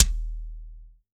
CYCdh_Kurz07-Kick02.wav